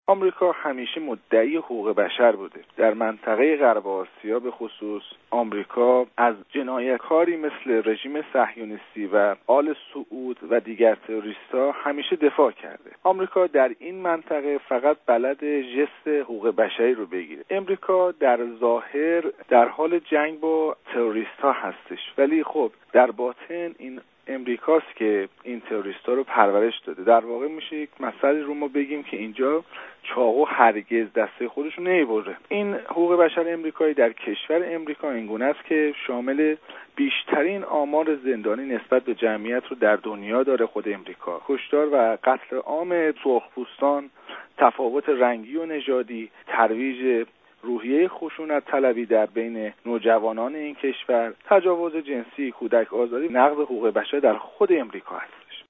Хабар